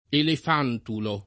[ elef # ntulo ]